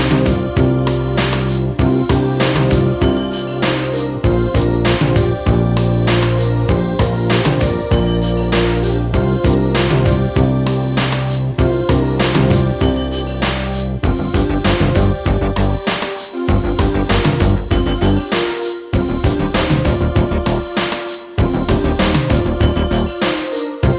Long Version Pop (1980s) 6:27 Buy £1.50